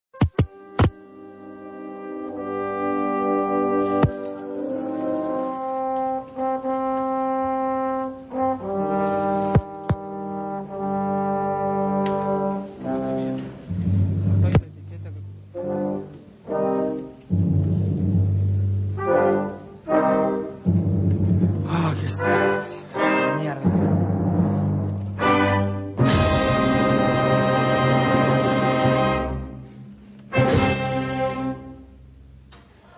Flórez is not patient with his microphone during the prelude.
florezrigolettoinsultshismicrophone.mp3